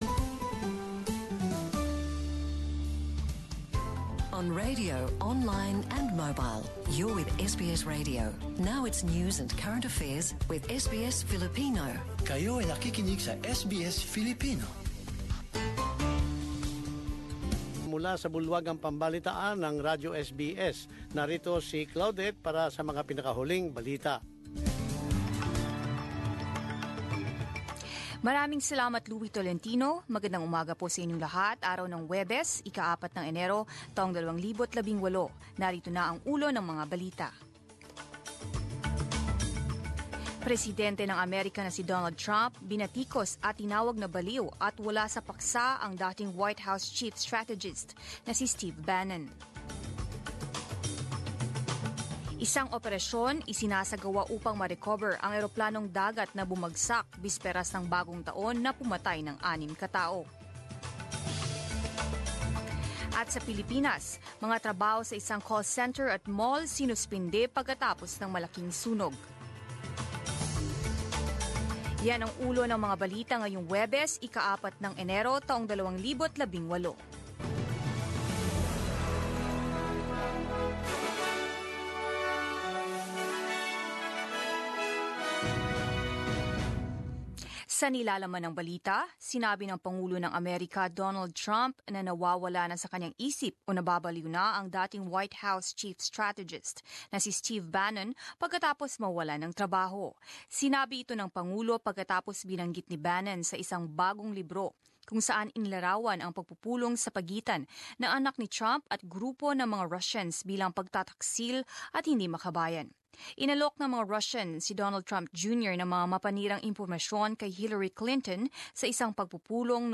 10 am News Bulletin
10 am News Bulletin in Filipino January 4, 2018, Thursday